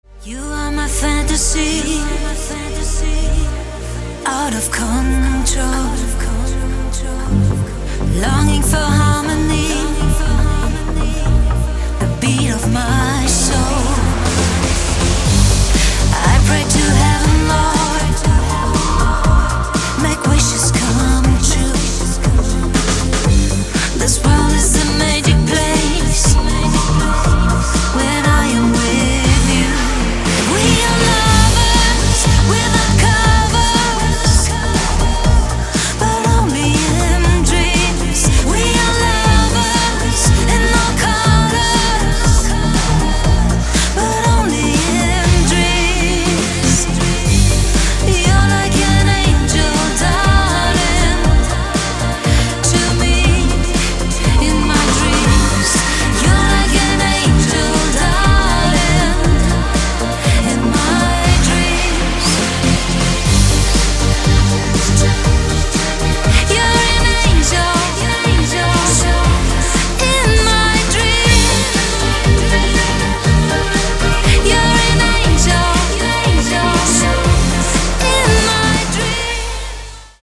• Качество: 320, Stereo
женский вокал
EDM
электронная музыка
Trance